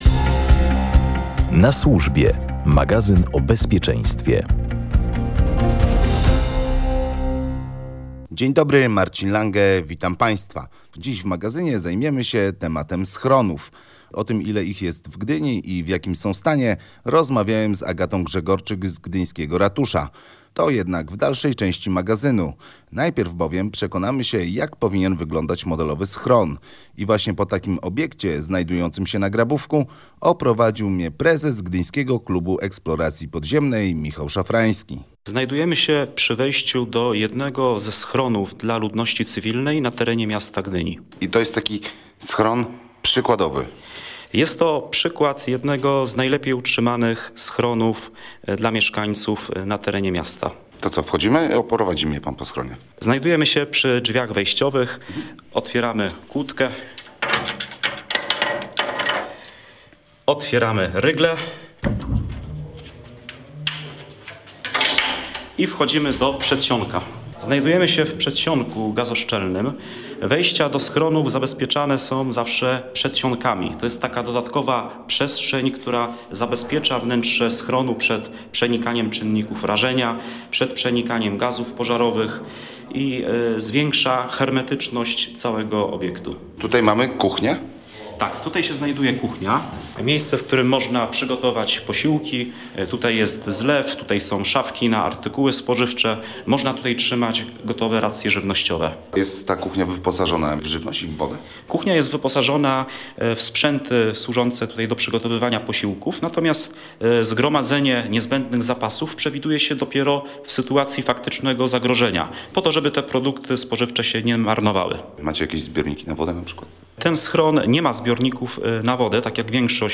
oprowadził naszego dziennikarza po schronie zlokalizowanym na Grabówku.